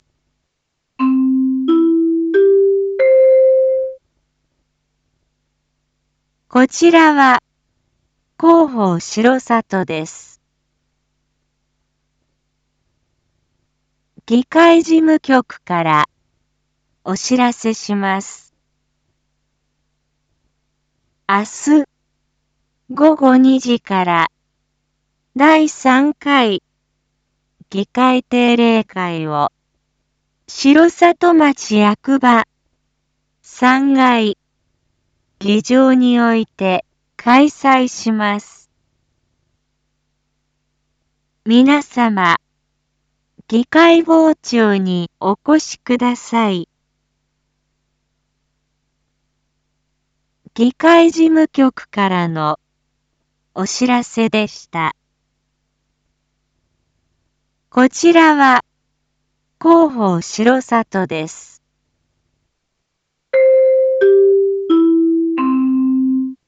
Back Home 一般放送情報 音声放送 再生 一般放送情報 登録日時：2023-09-14 19:01:08 タイトル：9/14 19時 第3回議会定例会 インフォメーション：こちらは広報しろさとです。